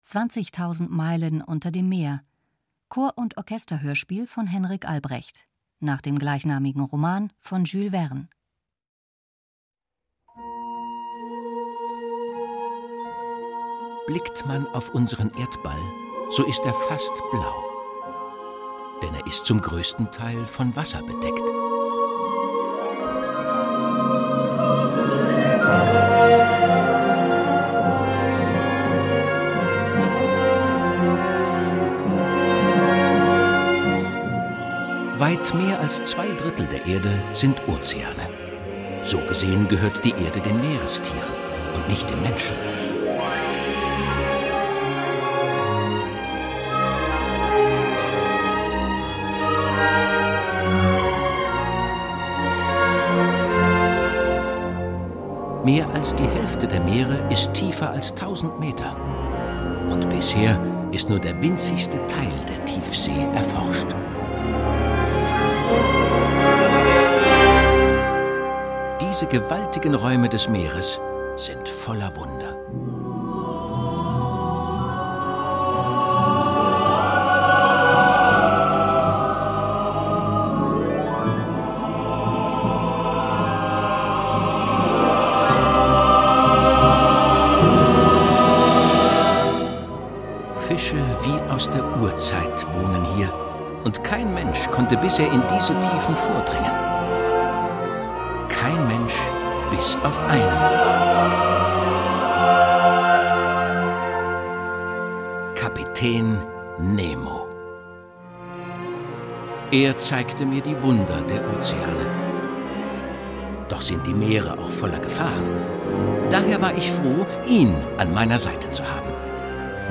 Orchesterhörspiel: 20.000 Meilen unter dem Meer ~ Hörspiele, Geschichten und Märchen für Kinder | Mikado Podcast